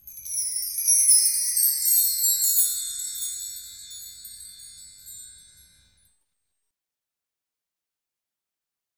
Index of /90_sSampleCDs/Roland LCDP03 Orchestral Perc/PRC_Wind Chimes2/PRC_Marktree